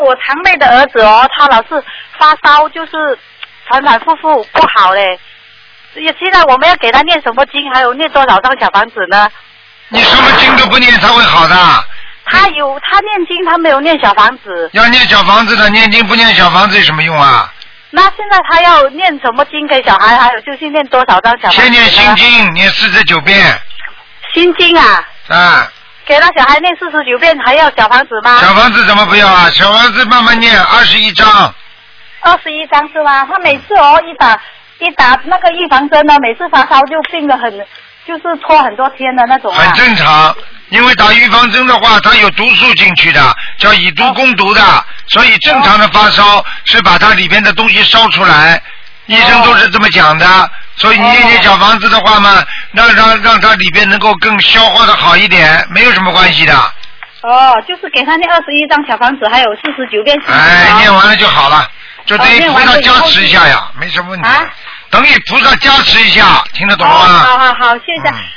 Tanya Jawab
Pendengar wanita (pendengar lain pada panggilan yang sama): Putra sepupu saya selalu mengalami demam, berulang kali sakitnya tak sembuh-sembuh.